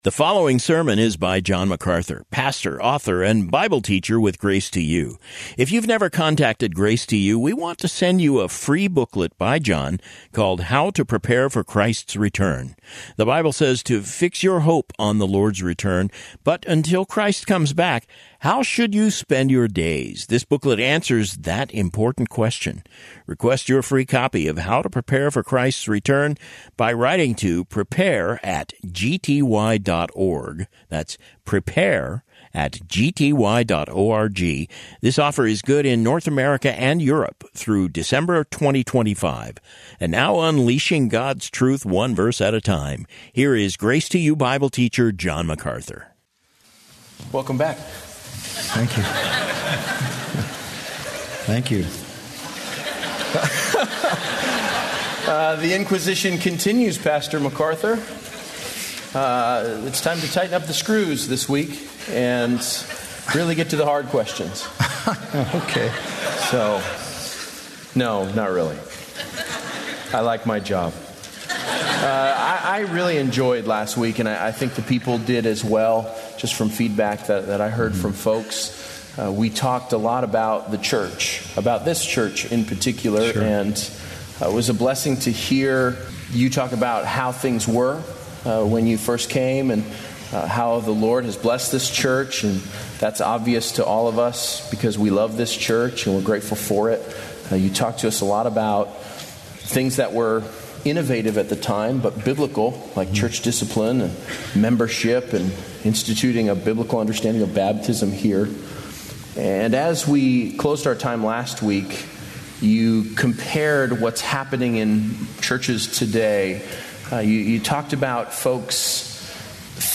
Bible Questions & Answers